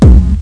bdrum-samples
linear-fx+echo.mp3